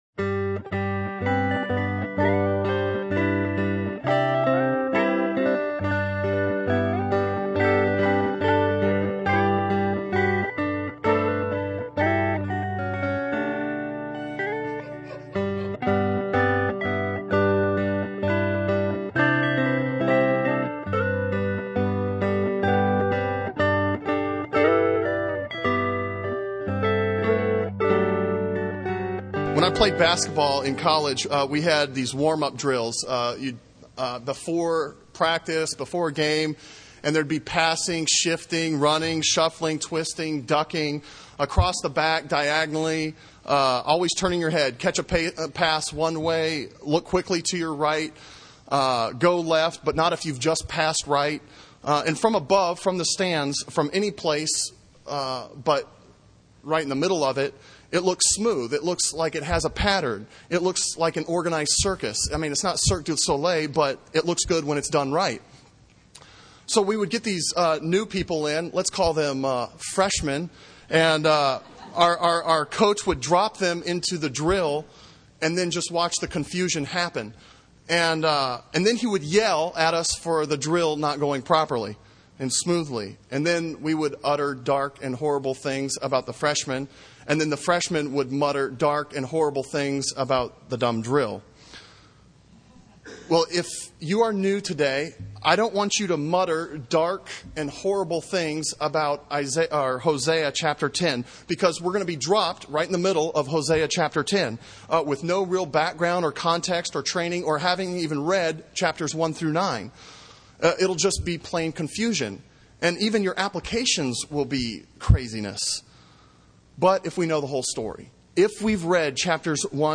Sermon on Hosea 10:1-6 from August 10